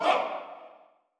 Hoooh.wav